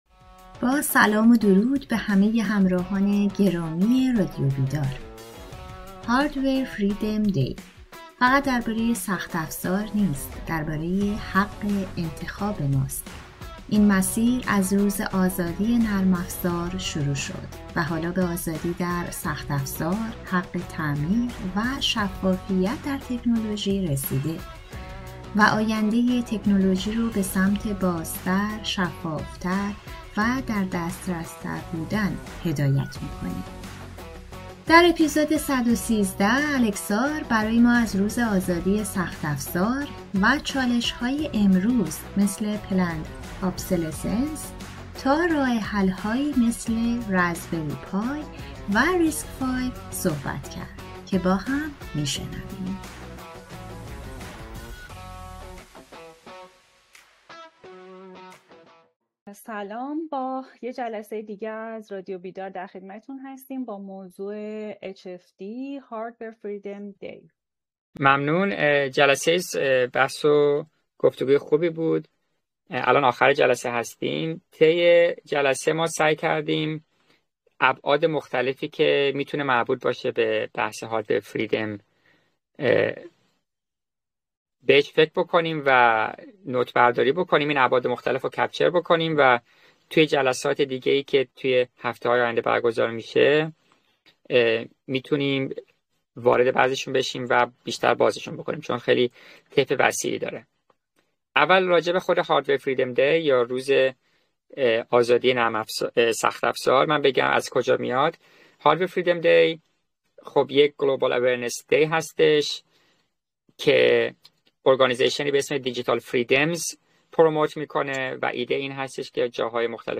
این گفتگو با مرور ریشه‌های Hardware Freedom در جنبش Software Freedom آغاز می‌شود و نشان می‌دهد که آزادی در تکنولوژی، صرفاً به نرم‌افزار محدود نیست.